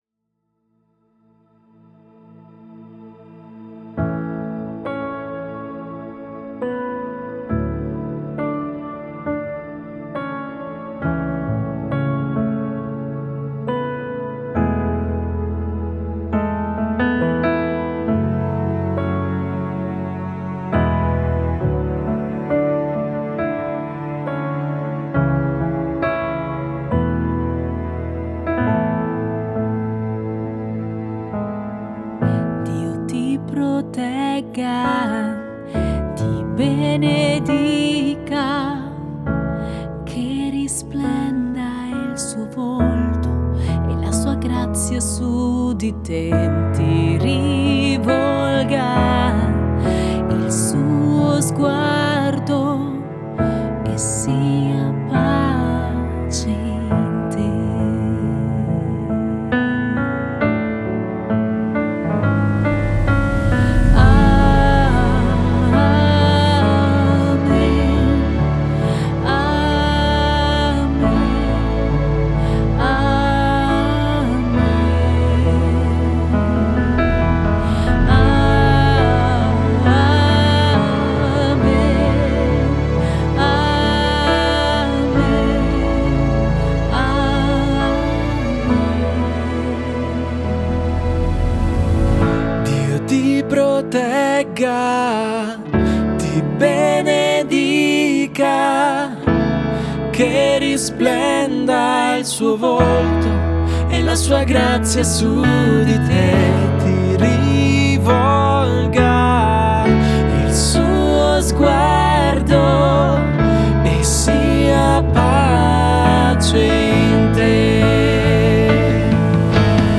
Chitarra
Pianoforte
Violino
Tastiera digitale
Batteria acustica